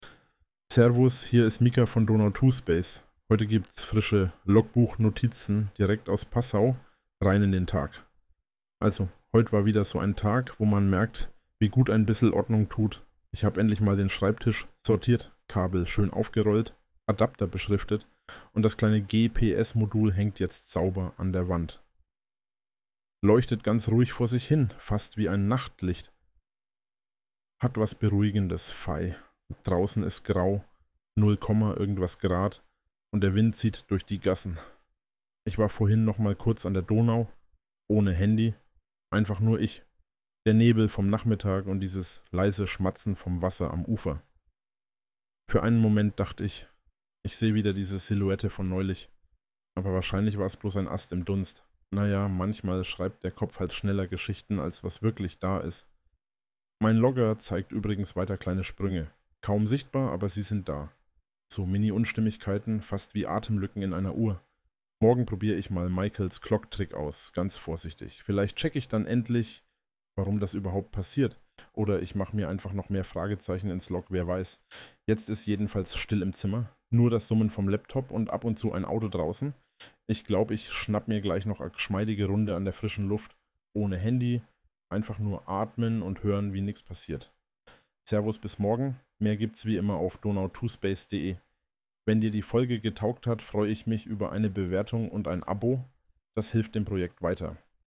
Hinweis: Dieser Inhalt wurde automatisch mit Hilfe von KI-Systemen (u. a. OpenAI) und Automatisierungstools (z. B. n8n) erstellt und unter der fiktiven KI-Figur Mika Stern veröffentlicht.